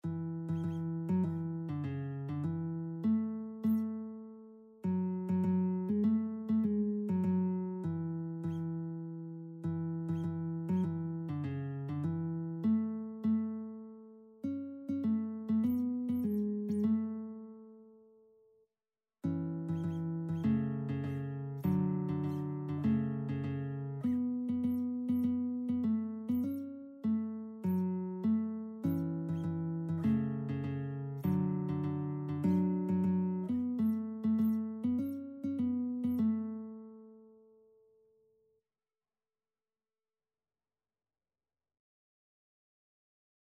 Christian Christian Lead Sheets Sheet Music Standing on the Promises
A major (Sounding Pitch) (View more A major Music for Lead Sheets )
4/4 (View more 4/4 Music)
Classical (View more Classical Lead Sheets Music)